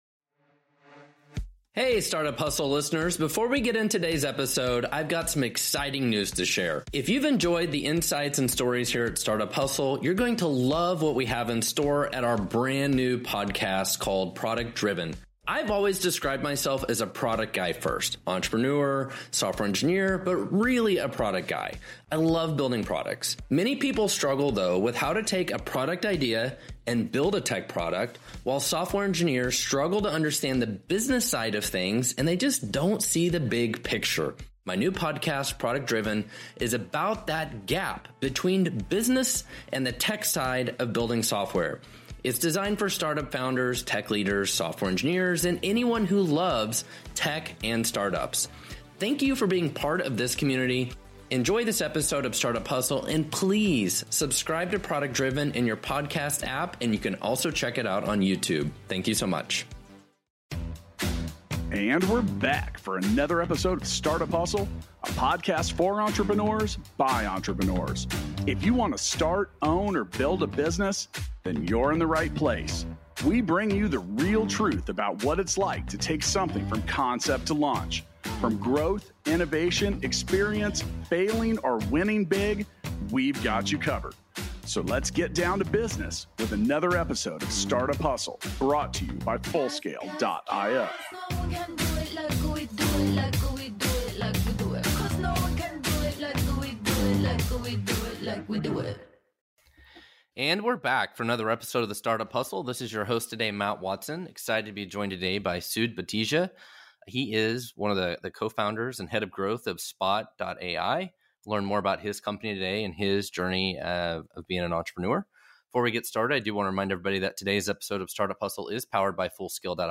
for a conversation about creating intelligence and analytics through video software platforms. Dive into the various capabilities of video software, how Spot AI manages all the data they receive from their clients, and how they track and provide valuable analytics from videos.